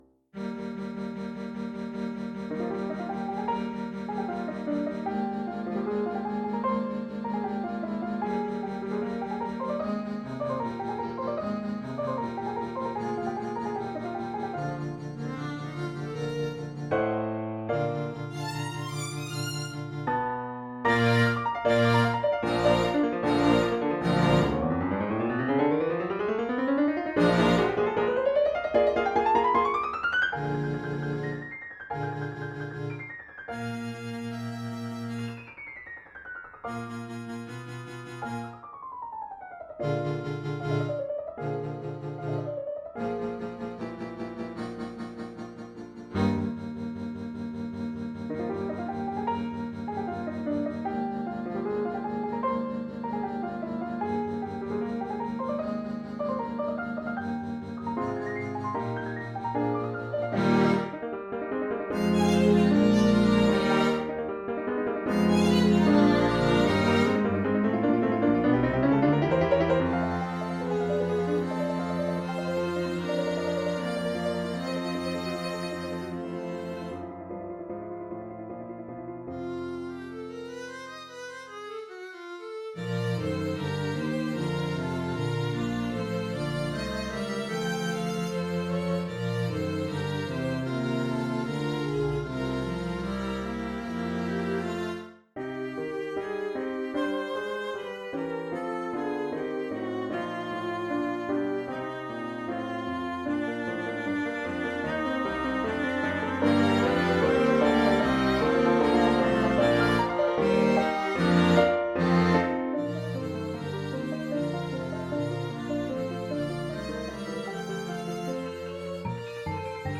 Soundbite 4th Movt
have had to resort to midi files
For Violin, Viola, Cello, Bass and Piano